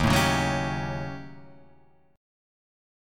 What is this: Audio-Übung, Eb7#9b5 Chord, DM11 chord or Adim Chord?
Eb7#9b5 Chord